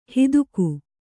♪ hiduku